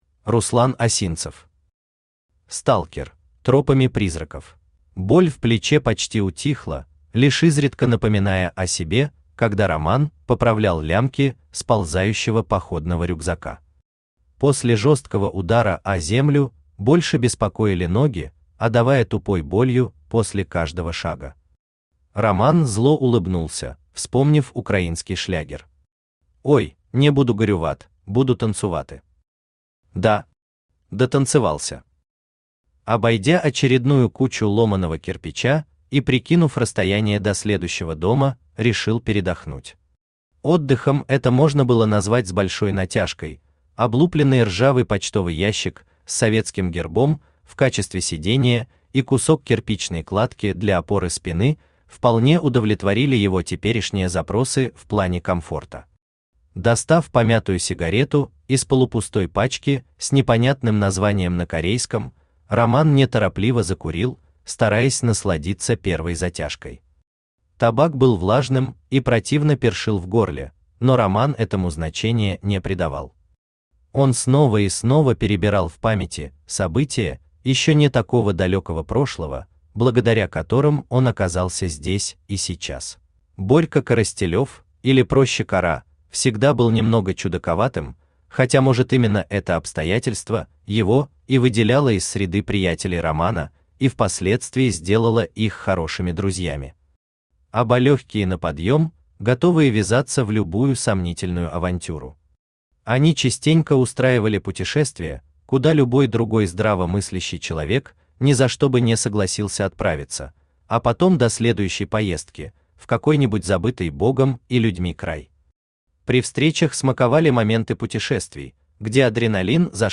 Тропами Призраков Автор Руслан Осинцев Читает аудиокнигу Авточтец ЛитРес.